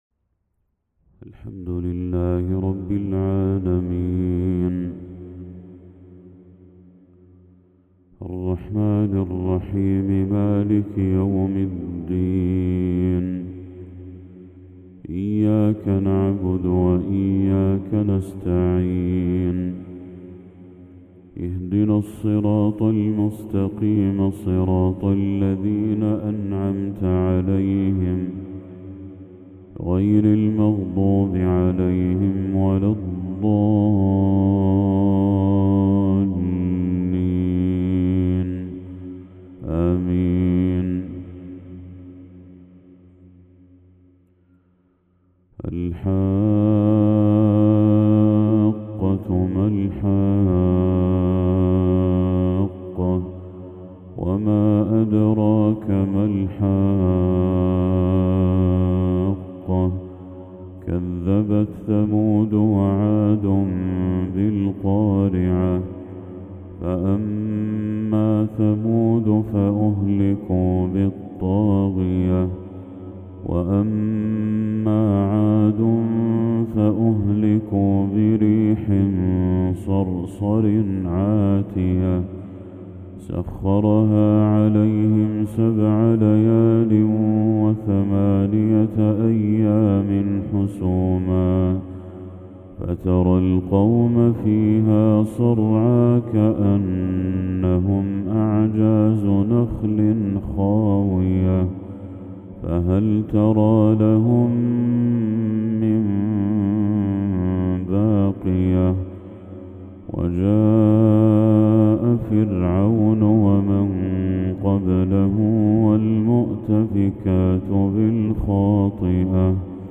تلاوة شجيِّة للشيخ بدر التركي سورة الحاقة كاملة | فجر 5 ذو الحجة 1445هـ > 1445هـ > تلاوات الشيخ بدر التركي > المزيد - تلاوات الحرمين